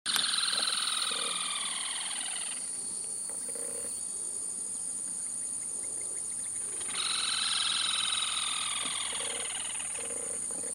Sanã-parda (Laterallus melanophaius)
Nome em Inglês: Rufous-sided Crake
Fase da vida: Adulto
Localidade ou área protegida: Parque Nacional El Palmar
Condição: Selvagem
Certeza: Gravado Vocal